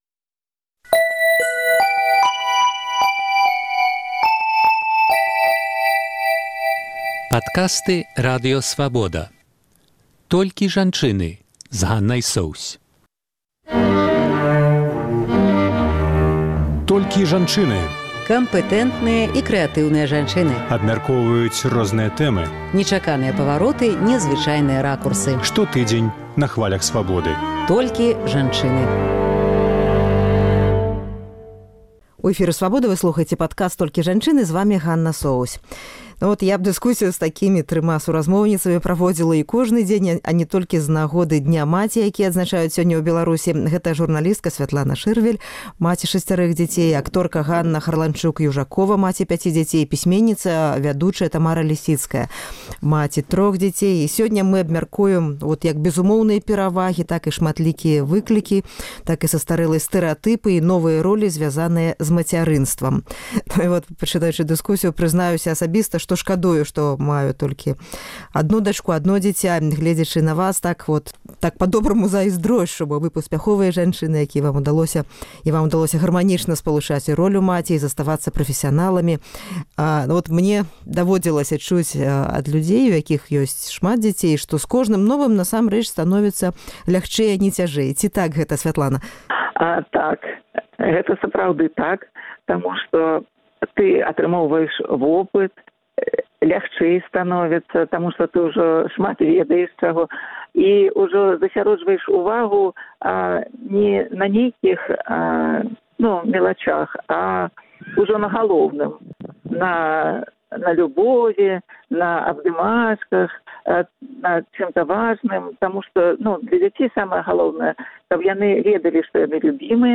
Kампэтэнтныя і крэатыўныя жанчыны абмяркоўваюць розныя тэмы, нечаканыя павароты, незвычайныя ракурсы.